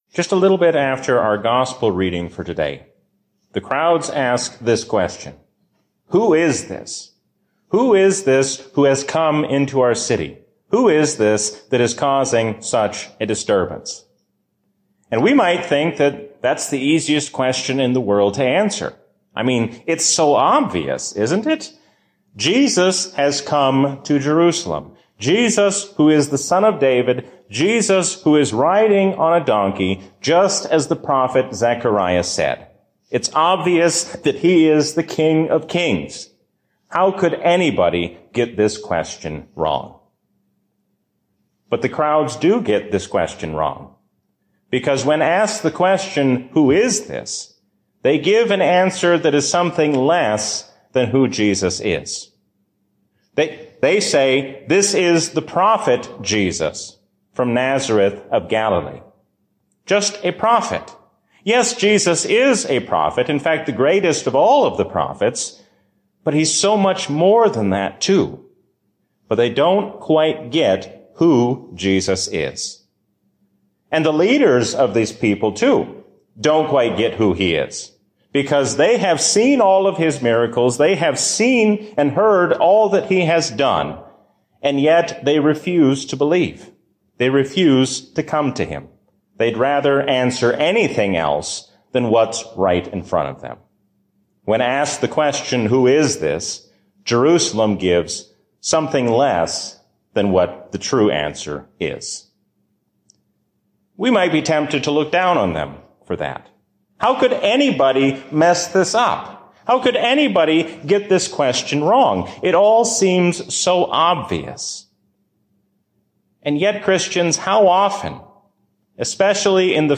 A sermon from the season "Trinity 2024." Be specific about who God is, because it is only from the true God that we receive all good things.